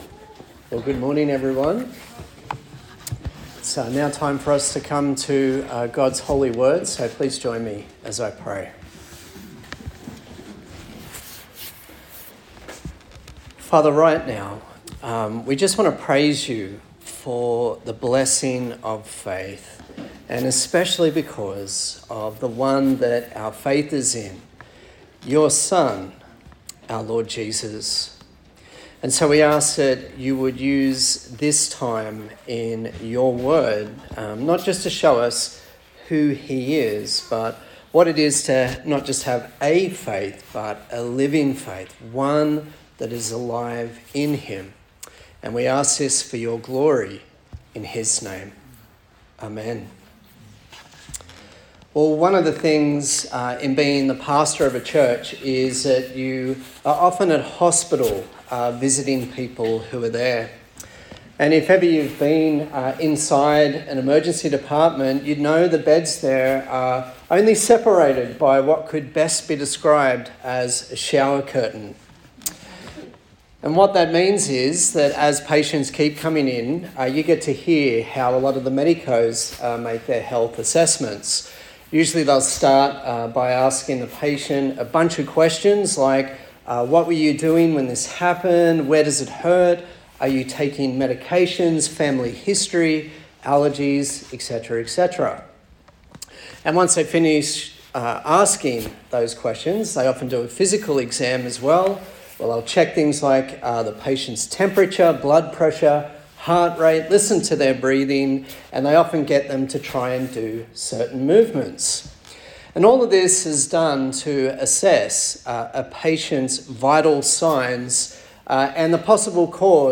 A sermon in the series on the book of James
Service Type: Sunday Service